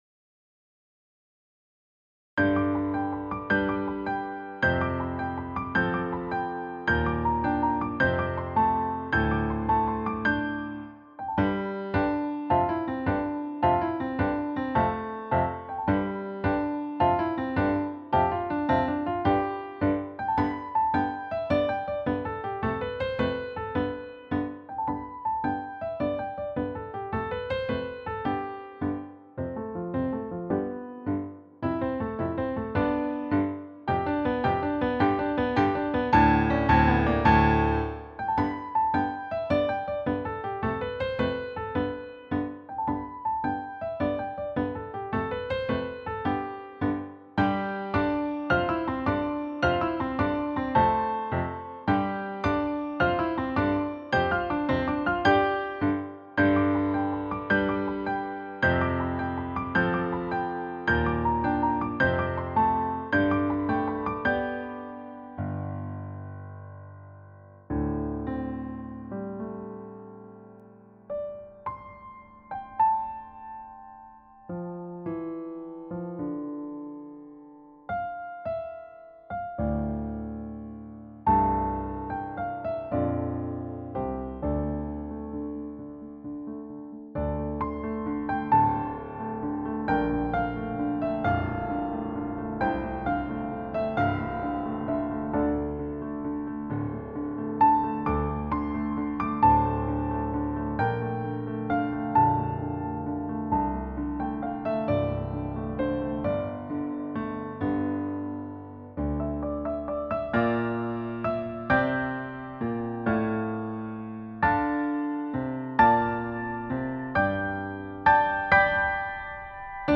contrasting moods of mystery, reflection, and triumph
Time Signature: Mixed meters